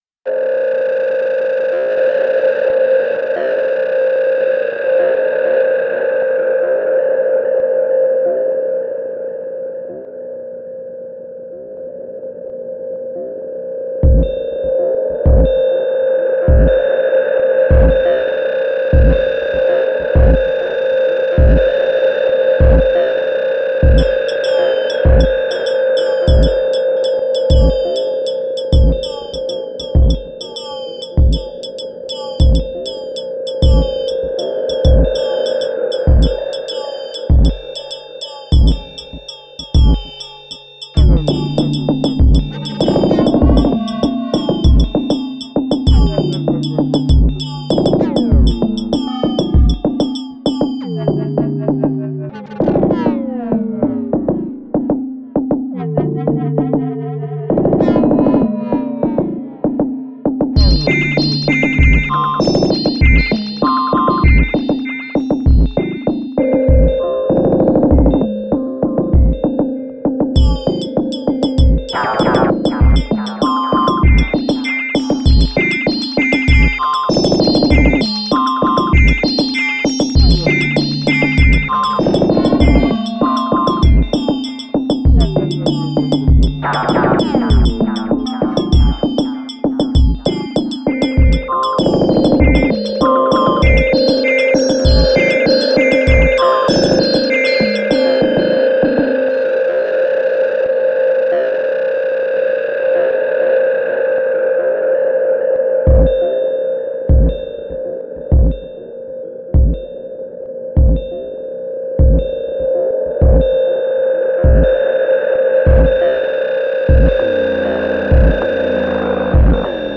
A new attempt – this time a dark, 98bpm illbient. I’m happy with the opening sound – it mimics tape delay.
Again 7xSY_Carbon, one pattern and mute/unmute plus retrig mod. And little compression in Audacity.